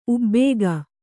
♪ ubbēga